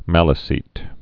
(mălə-sēt) or Mal·e·cite (-sīt)